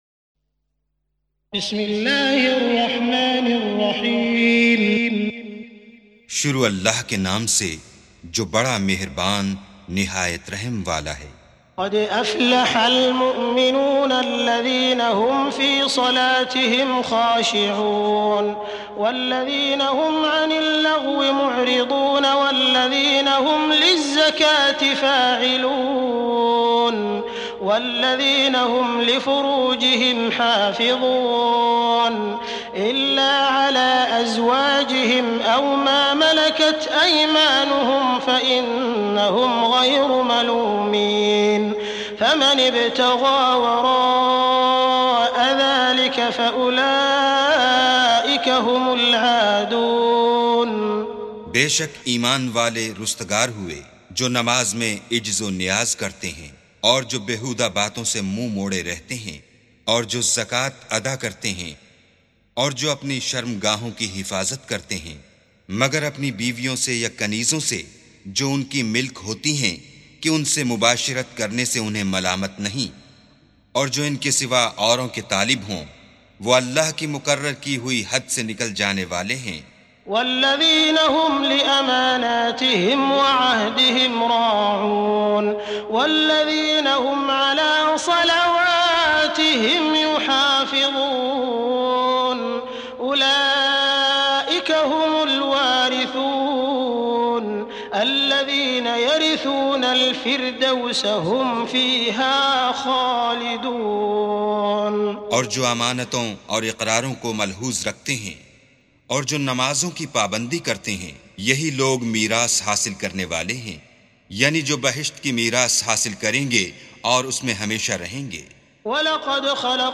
سُورَةُ المُؤۡمِنُونَ بصوت الشيخ السديس والشريم مترجم إلى الاردو